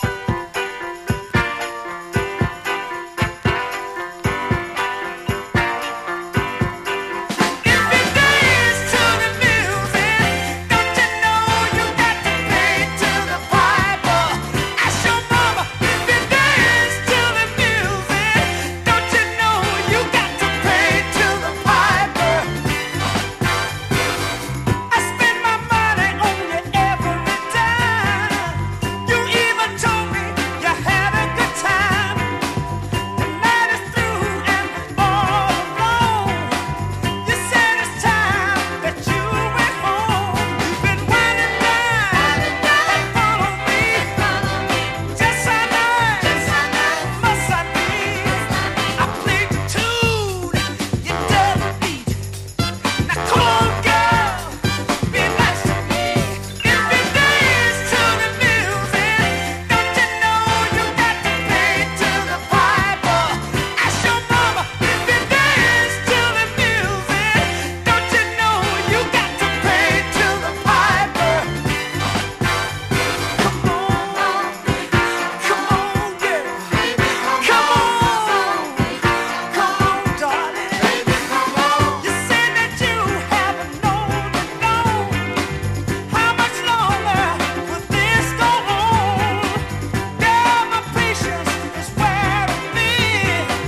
高揚感溢れるヤング・ノーザンソウル！